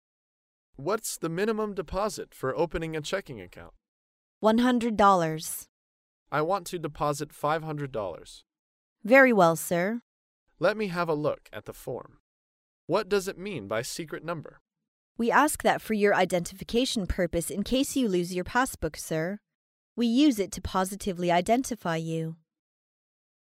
在线英语听力室高频英语口语对话 第420期:开立支票账户(2)的听力文件下载,《高频英语口语对话》栏目包含了日常生活中经常使用的英语情景对话，是学习英语口语，能够帮助英语爱好者在听英语对话的过程中，积累英语口语习语知识，提高英语听说水平，并通过栏目中的中英文字幕和音频MP3文件，提高英语语感。